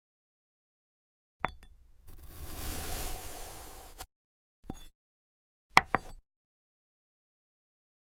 Glass Apple ASMR – clean sound effects free download
clean Mp3 Sound Effect Glass Apple ASMR – clean cuts, calm sounds.